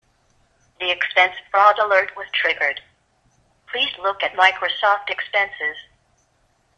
Basically, if you provide a “thresholdValue” of zero (e.g. it doesn’t matter what value was exceeded), then I create a TwiML message that uses a woman’s voice to tell the call recipient that a threshold was exceeded and some action is required.
Sure enough, I received a phone call.